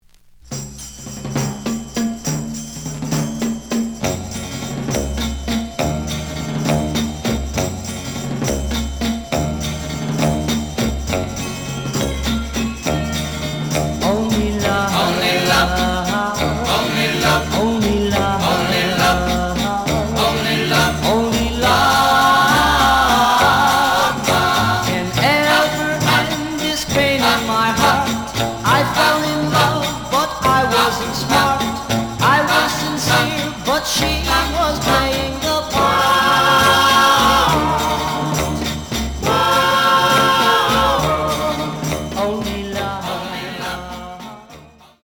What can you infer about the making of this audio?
The audio sample is recorded from the actual item. Slight edge warp. But doesn't affect playing. Plays good.)